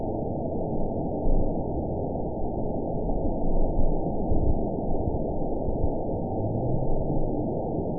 event 920307 date 03/14/24 time 20:54:01 GMT (1 year, 1 month ago) score 7.40 location TSS-AB03 detected by nrw target species NRW annotations +NRW Spectrogram: Frequency (kHz) vs. Time (s) audio not available .wav